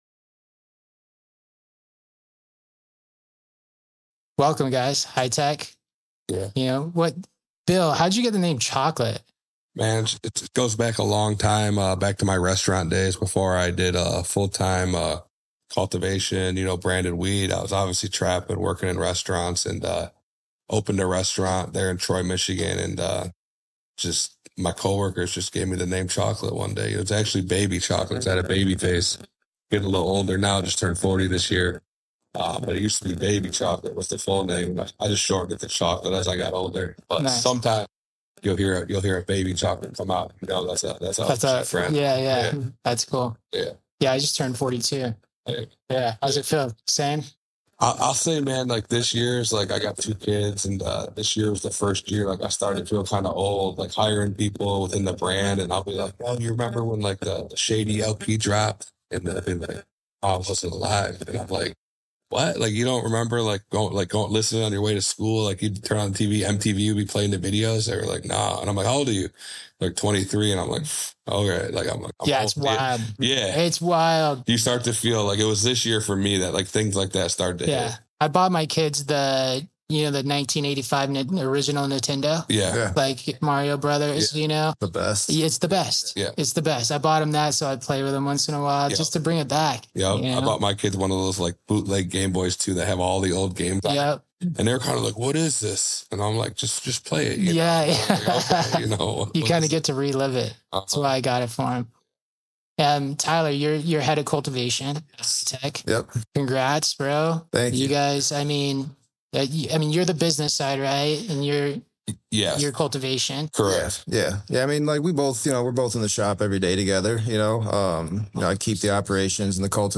A Growers podcast hosted by a panel of growers based out of Southern California. We bring you perspective, best practices and discuss the latest technology in the Cannabis Industry.